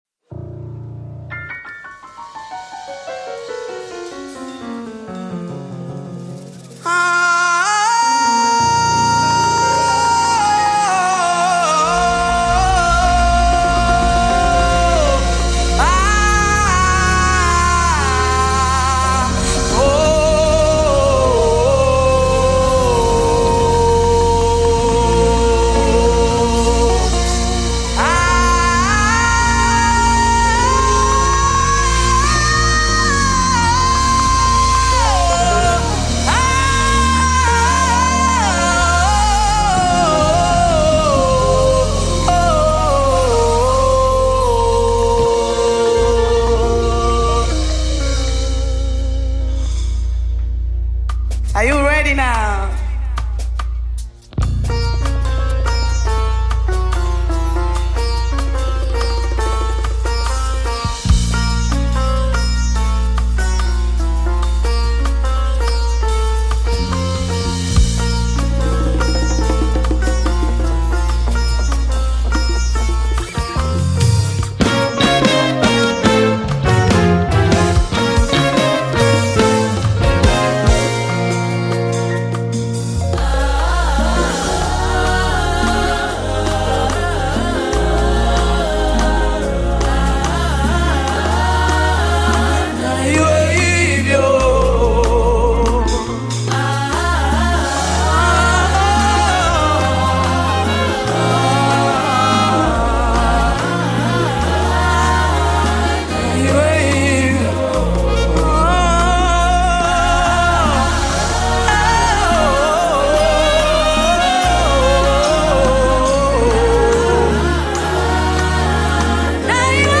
Gospel music track